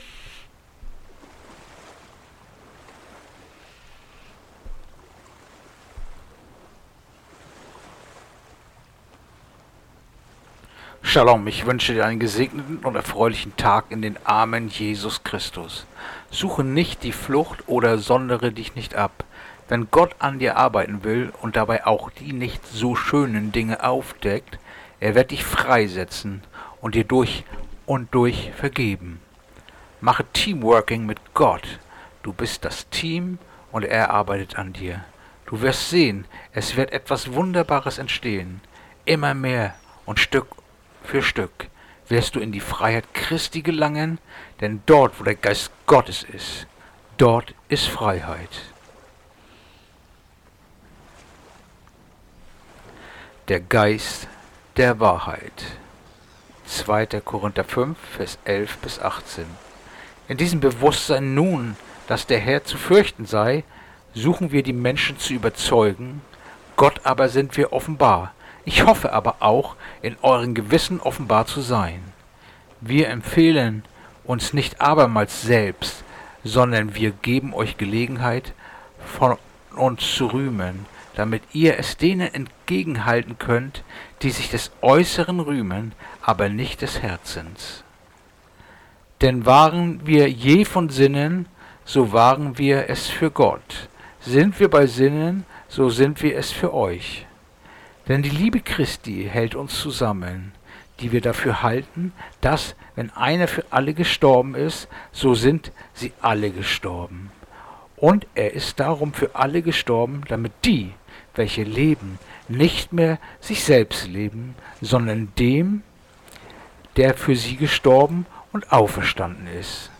Andacht-vom-12-Juni-2-Korinther-511-18